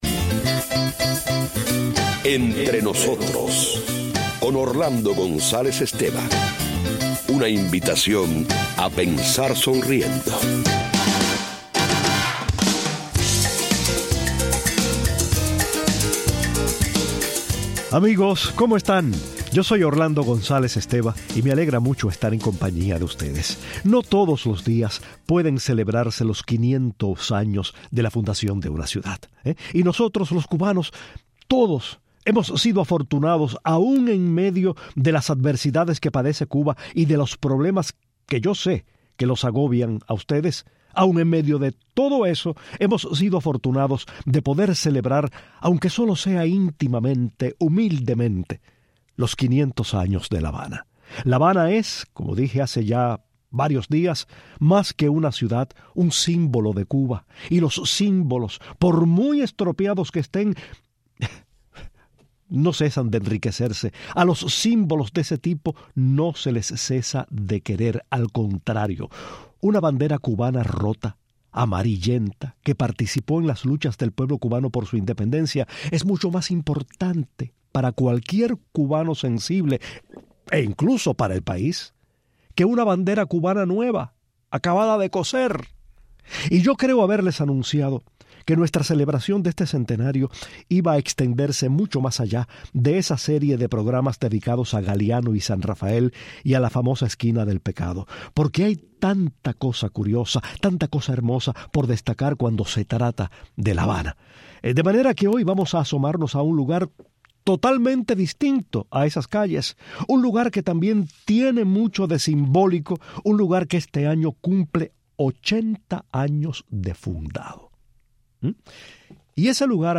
La celebración del V centenario de La Habana no debe excluir la de los 80 años del más célebre de los centros nocturnos de la isla. Hoy contamos su historia, hacemos énfasis en algunas curiosidades y escuchamos a algunos de los artistas que se presentaron en él.